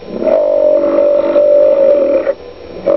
bison.wav